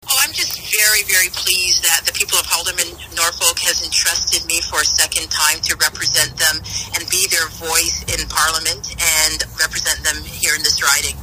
Speaking with the media over the phone, she was thrilled to be given the chance to work for the people of the riding for four more years.